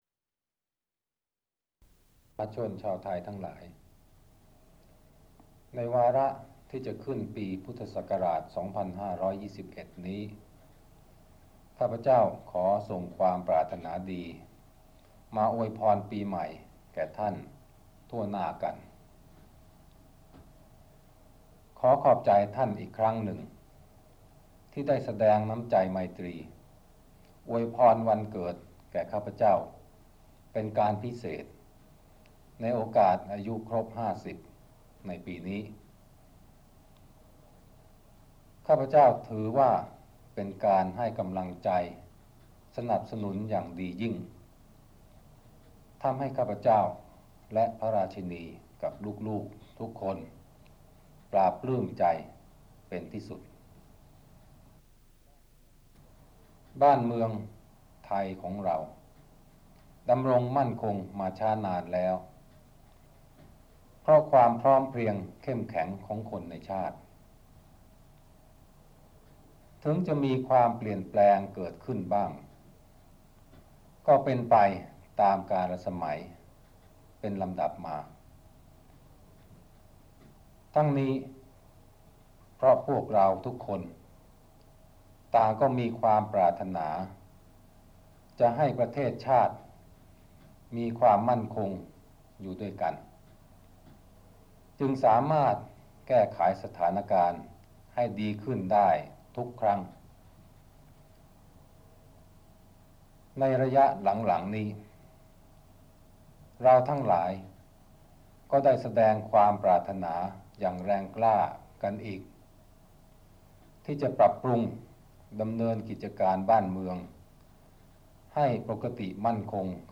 พระราชดำรัสพระบาทสมเด็จพระเจ้าอยู่หัว ในวันขึ้นปีใหม่ 2521